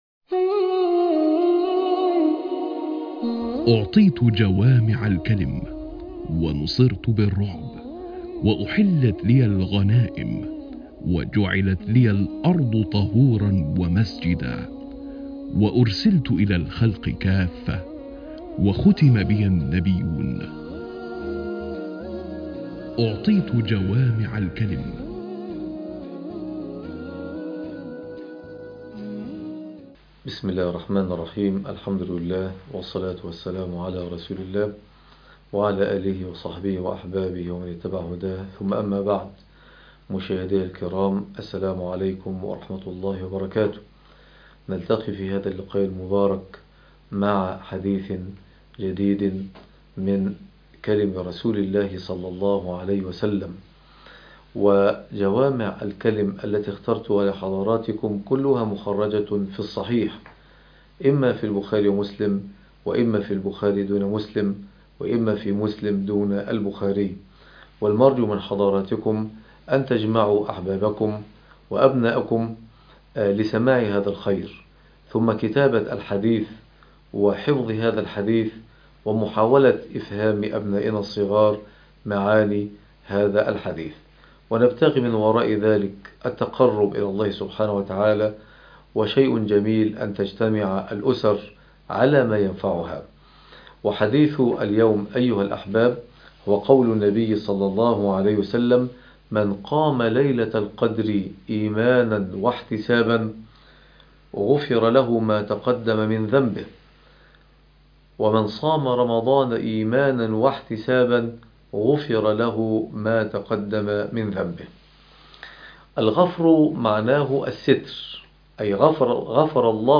عنوان المادة قيام ليلة القدر (13/05/2020) جوامع الكلم